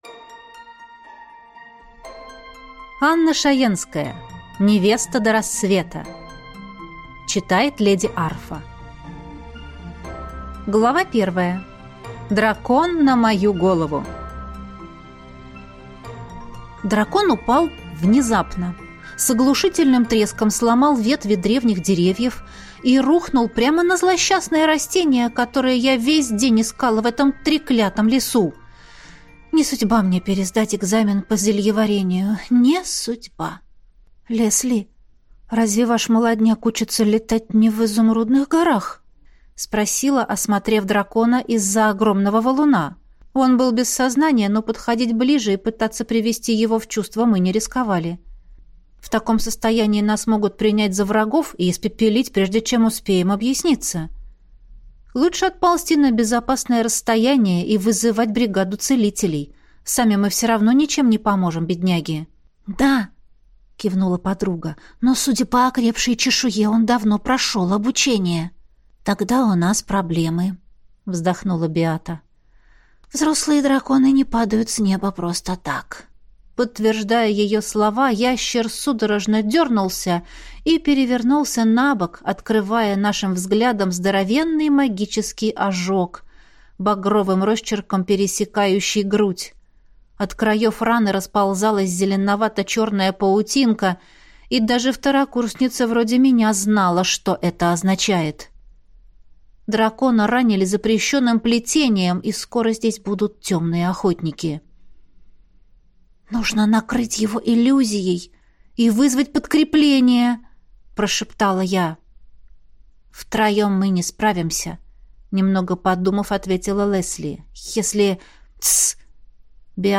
Аудиокнига Невеста до рассвета | Библиотека аудиокниг